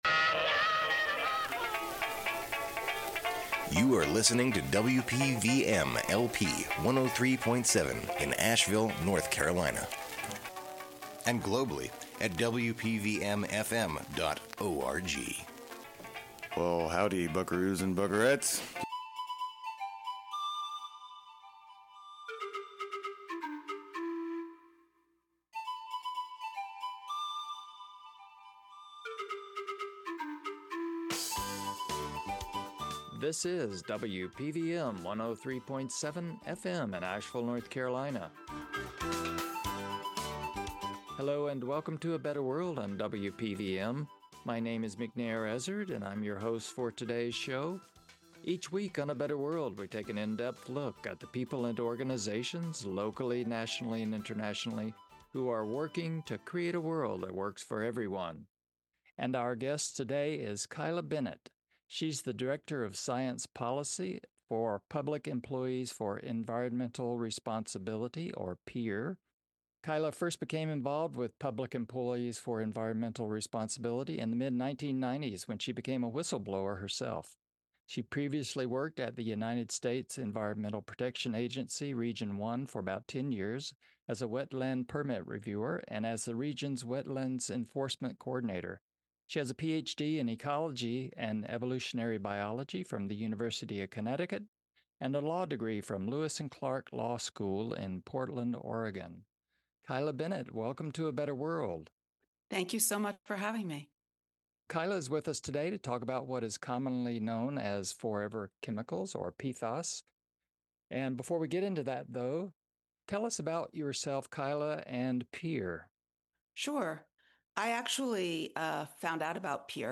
A Better World radio show